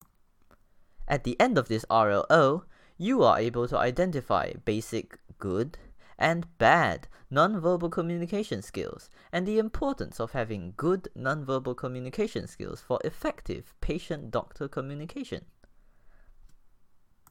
Narration audio (MP3)